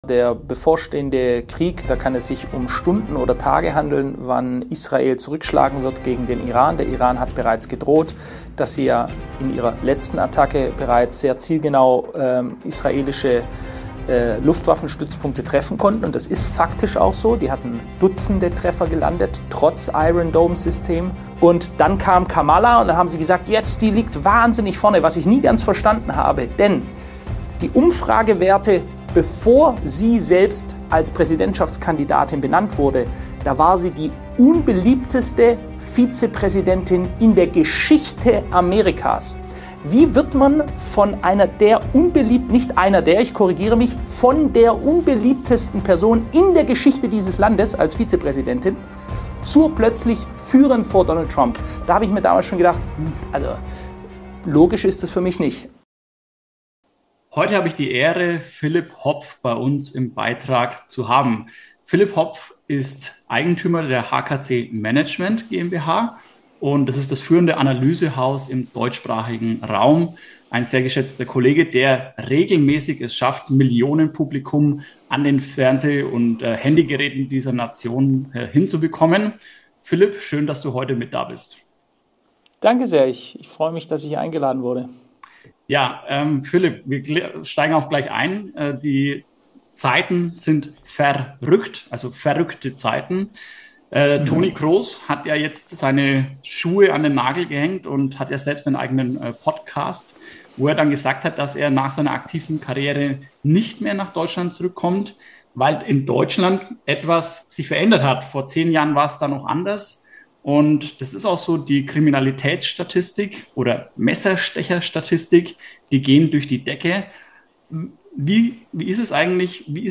In diesem explosiven Gespräch